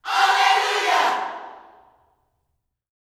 ALLELUJAH8.wav